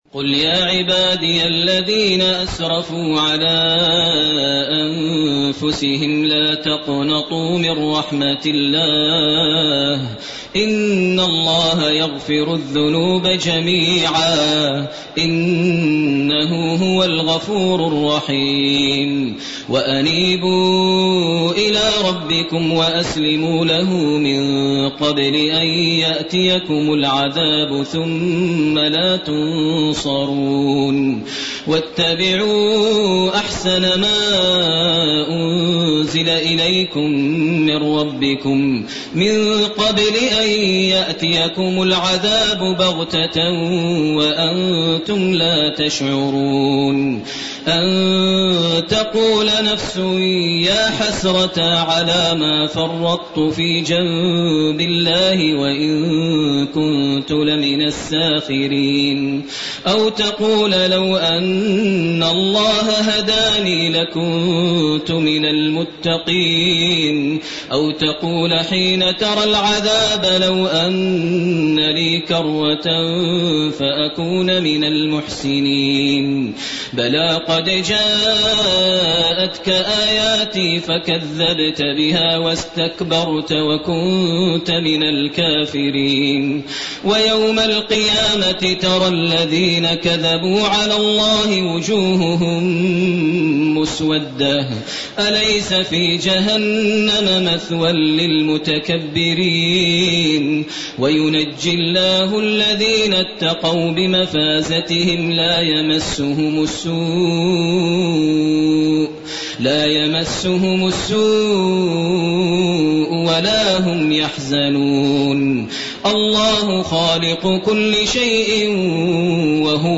سورة الزمر 54 الي أخرها سورة غافر > تراويح ١٤٢٩ > التراويح - تلاوات ماهر المعيقلي